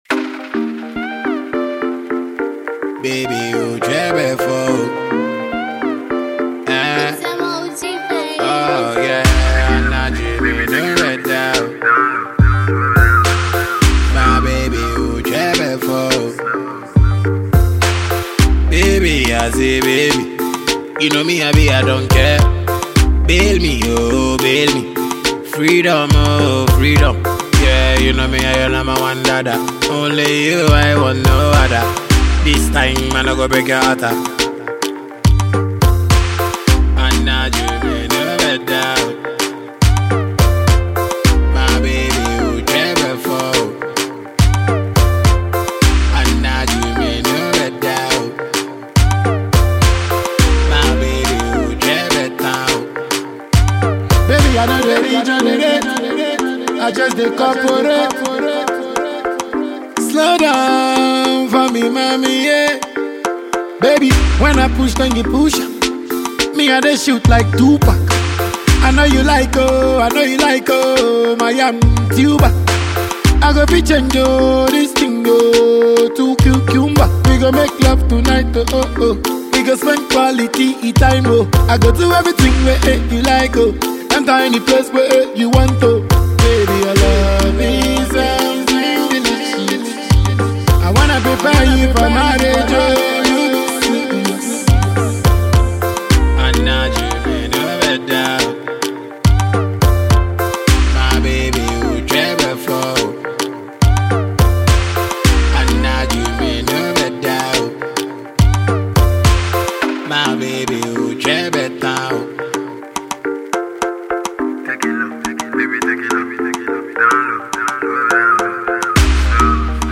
afrobeat song
controversial and explicit song